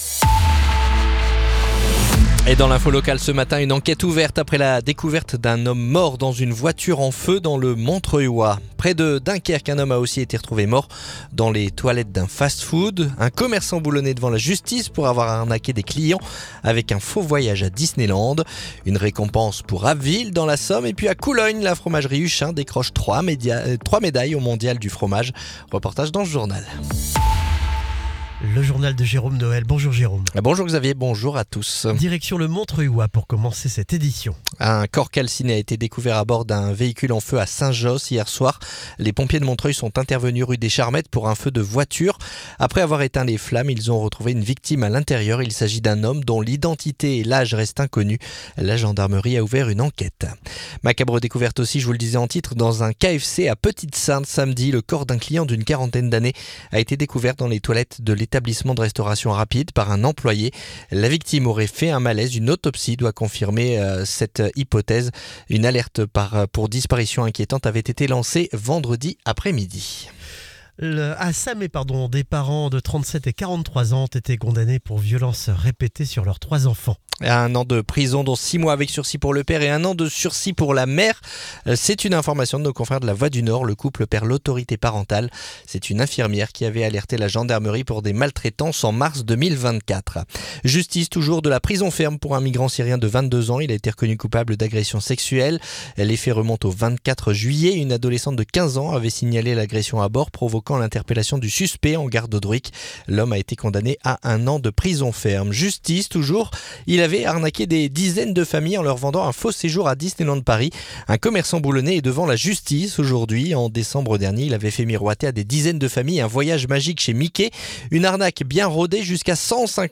Le journal du mardi 23 septembre